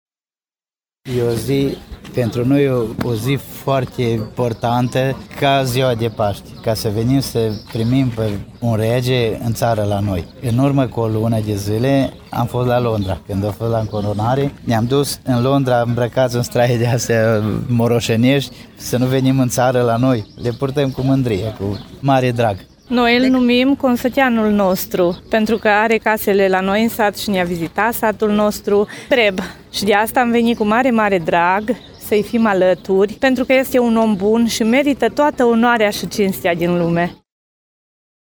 Doi soți au venit special pentru rege tocmai de la Maramureș, îmbrăcați în spectaculosul lor port tradițional: